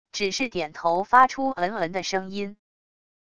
只是点头发出唔嗯的声音wav音频